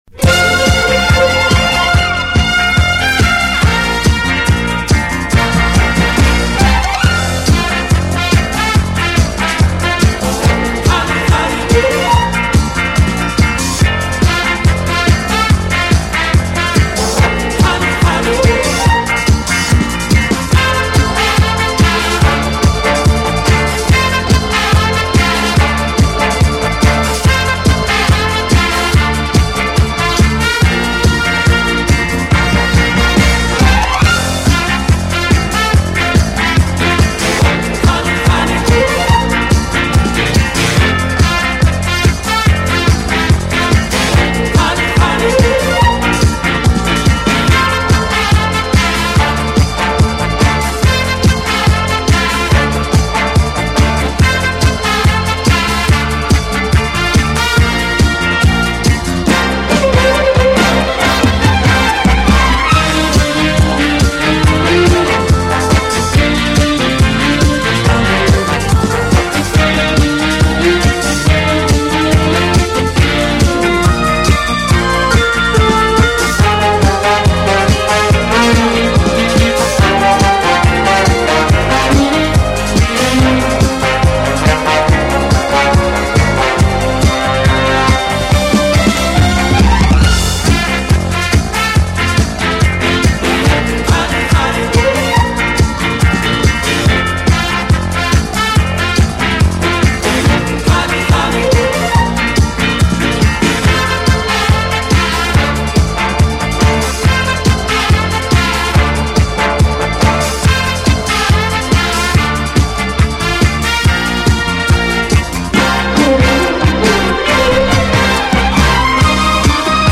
有动感，更有层次感；既有激情，更有浪漫。